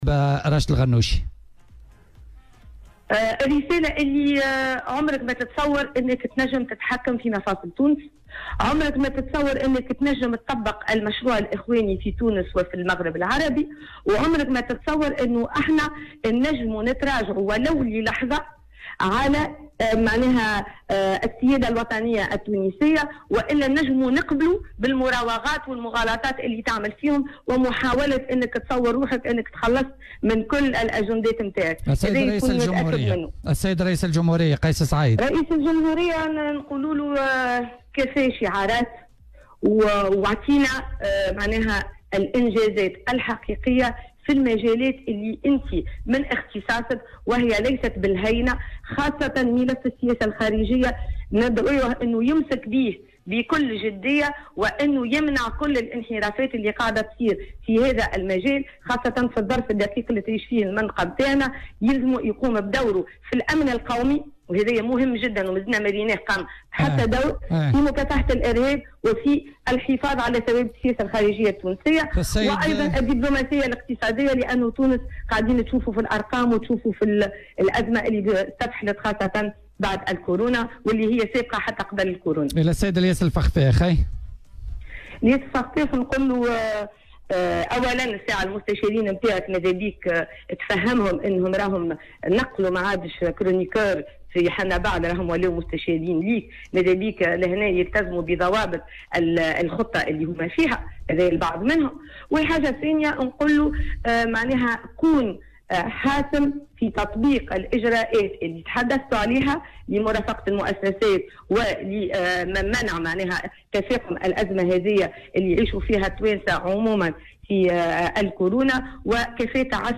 توجّهت رئيسة الحزب الدستوري الحر، عبير موسي في برنامج "بوليتيكا" اليوم برسائل إلى رئيس الجمهورية قيس سعيد، ورئيس الحكومة إلياس الفخفاخ وكذلك رئيس مجلس نواب الشعب راشد الغنوشي.